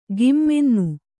♪ gimmennu